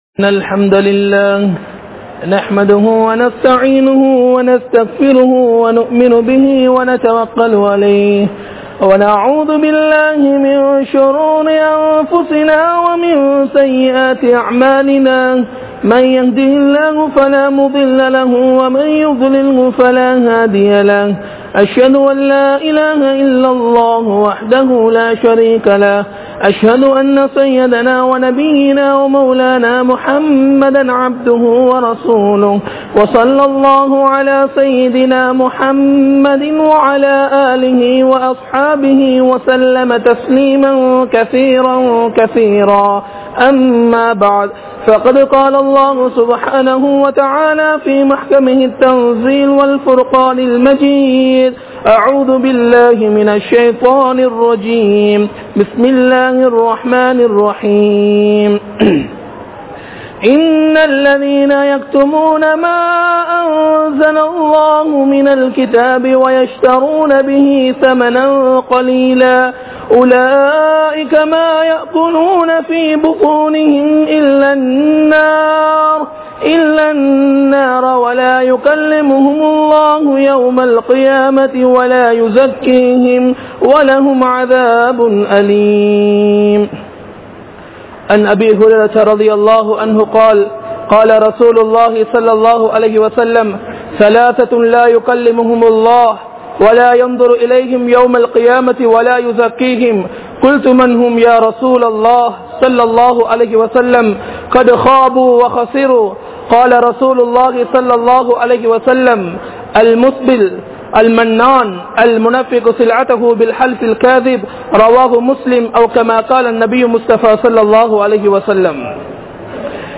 Allah`vin Saafaththai Tharum Paavangal (அல்லாஹ்வின் சாபத்தை தரும் பாவங்கள்) | Audio Bayans | All Ceylon Muslim Youth Community | Addalaichenai
Aluthgama, Dharga Town, Meera Masjith(Therupalli)